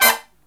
HIGH HIT13-R.wav